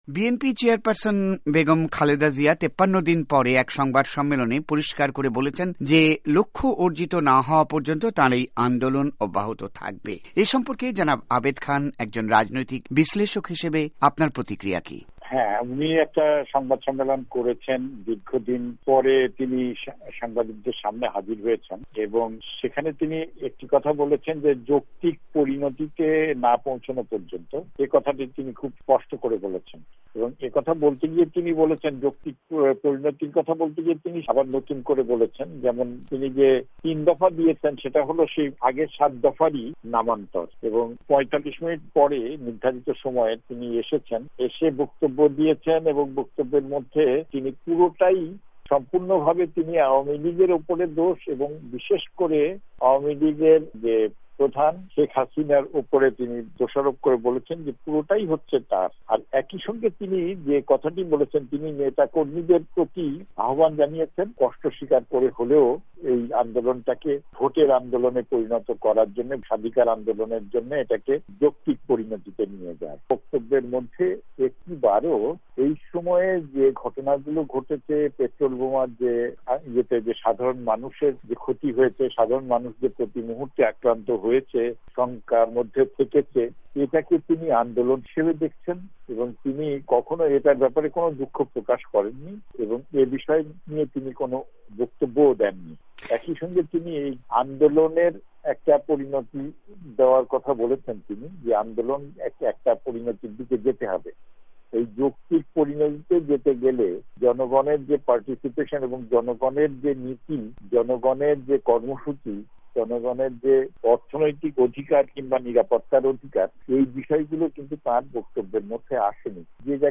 সাক্ষাতকার